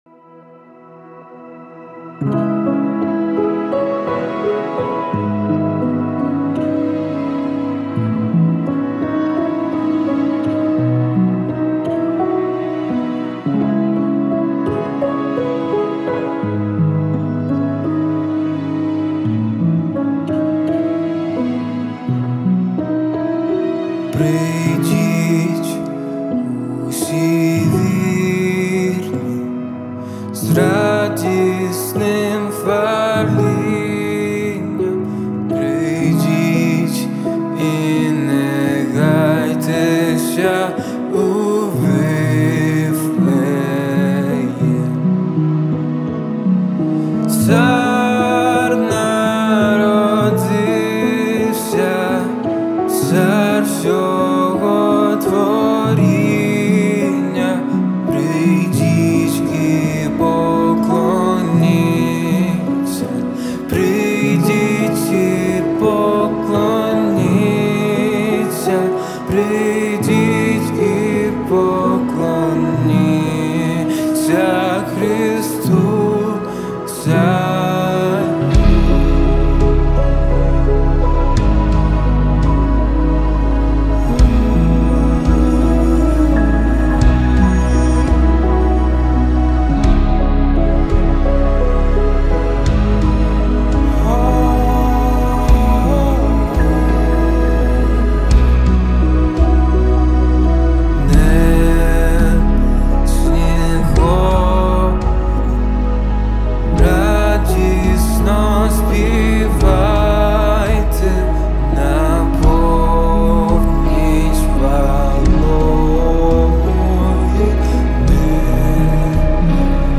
295 просмотров 197 прослушиваний 8 скачиваний BPM: 85